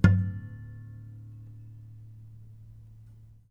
harmonic-10.wav